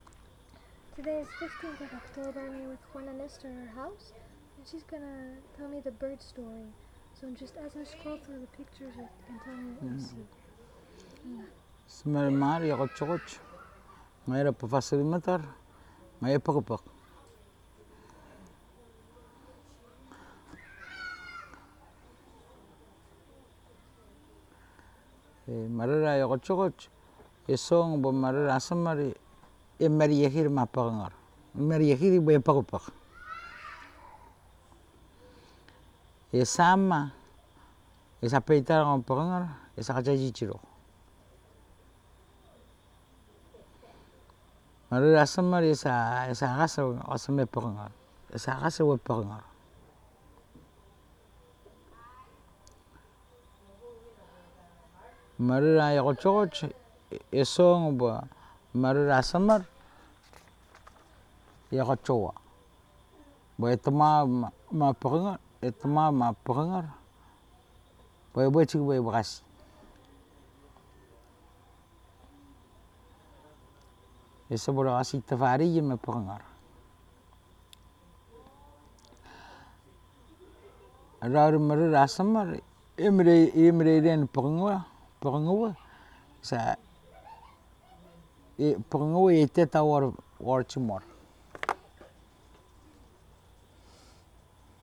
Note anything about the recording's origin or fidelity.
digital wav file recorded at 44.1 kHz/16 bit on Zoom H2N Echang, Koror, Palau